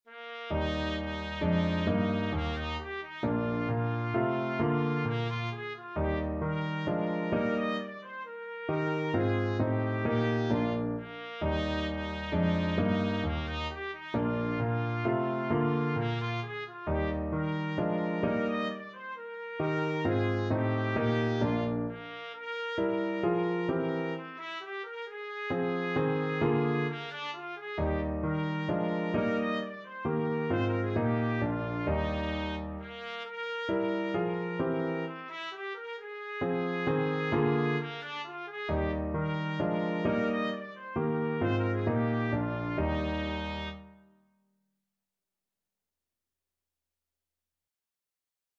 Trumpet
Eb major (Sounding Pitch) F major (Trumpet in Bb) (View more Eb major Music for Trumpet )
Bb4-Eb6
3/4 (View more 3/4 Music)
= 132 Allegro (View more music marked Allegro)
Classical (View more Classical Trumpet Music)
HaydnMinuet_TPT.mp3